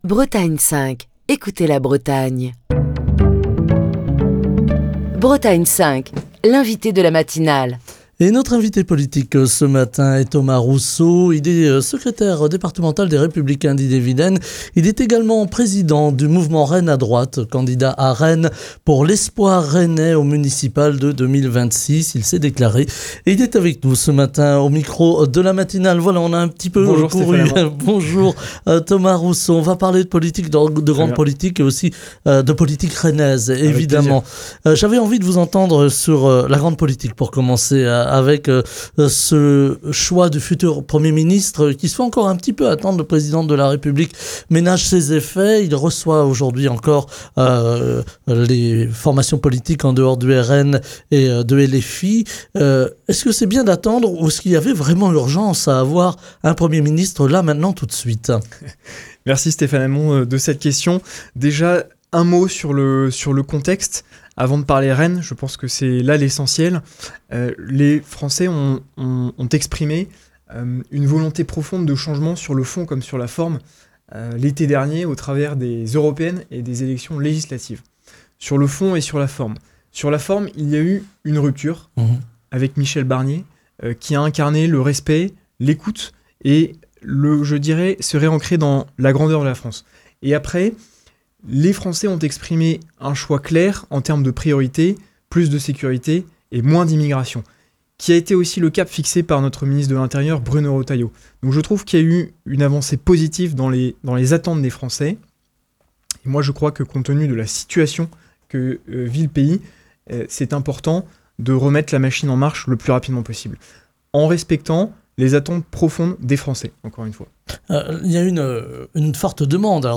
est l’invité politique de la matinale de Bretagne 5